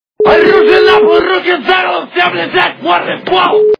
При прослушивании Ограбление - Оружие на пол, руки за голову. Всем лежать мордой в пол! качество понижено и присутствуют гудки.